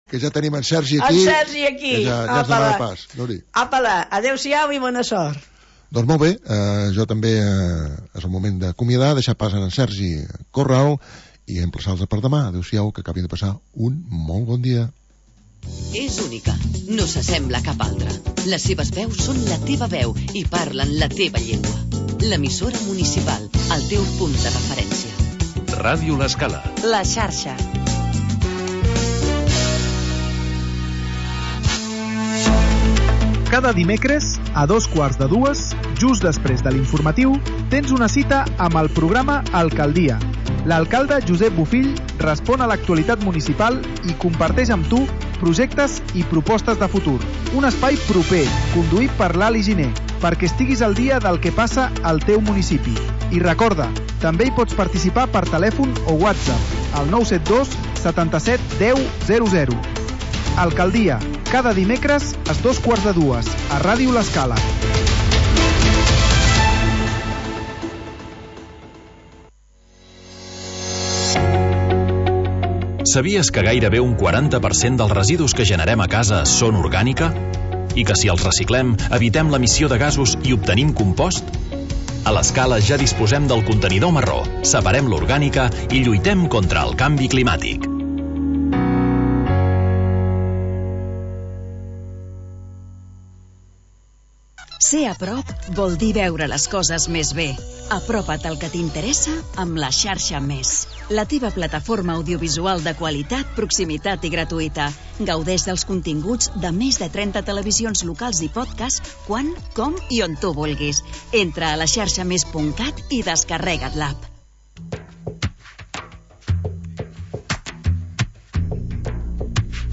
Magazin d'entretiment per acompanyar el migdiaompanyar